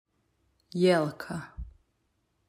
1. Jelka (click to hear the pronunciation)